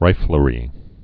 (rīfəl-rē)